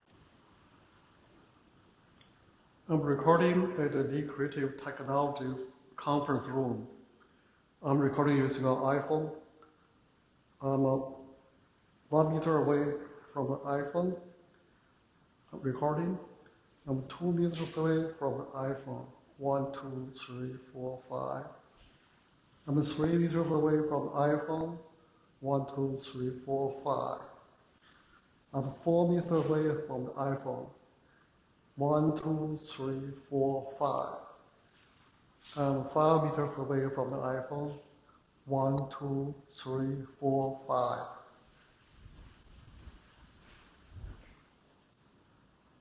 Demo 1 – Large Conference Room
Raw recording (iPhone 12 Pro):
Audio1_LargeRoom_iPhone12_Pro.wav